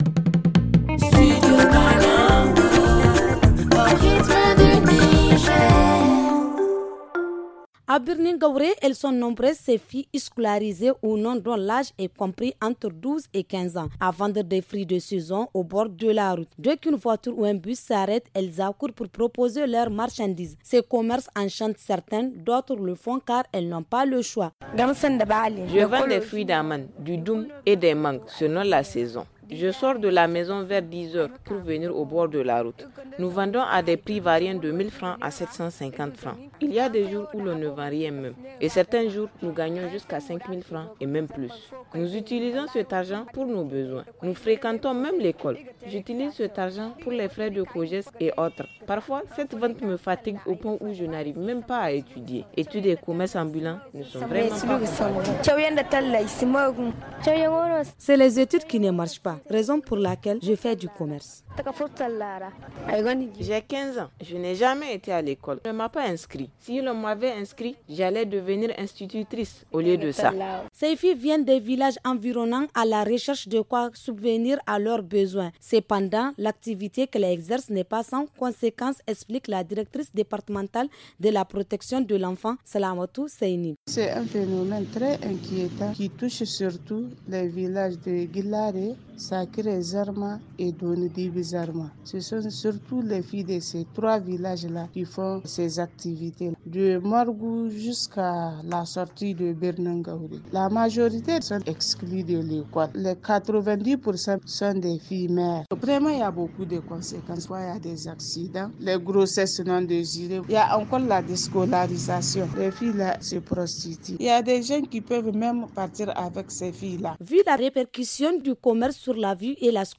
Cependant, ce commerce peut avoir des conséquences sur leur scolarité et leur vie. Reportage